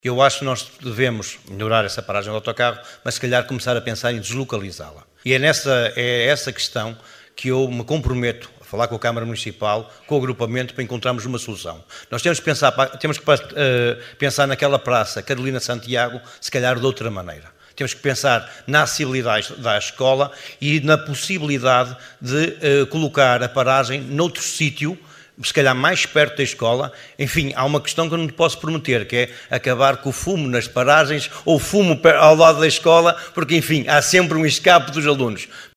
Excertos da última assembleia municipal, realizada a 16 de Dezembro no Teatro Valadares em Caminha.